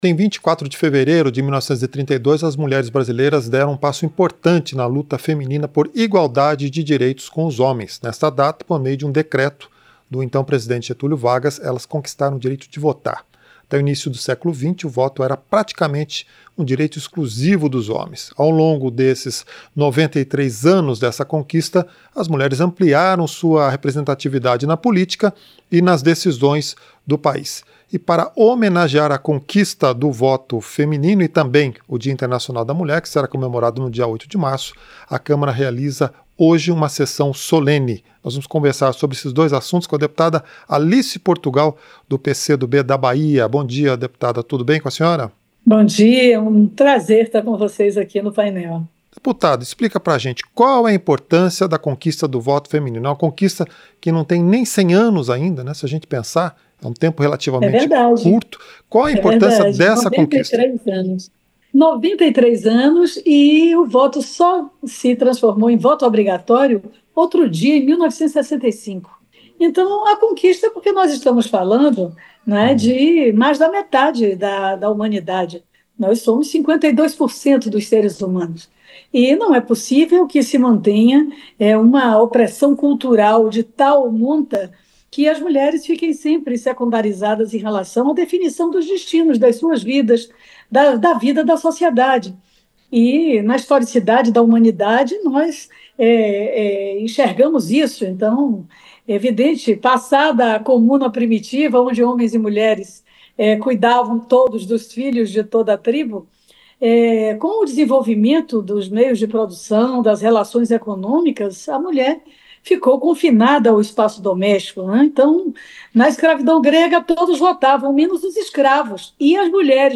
Entrevista - Dep. Alice Portugal (PCdoB-BA)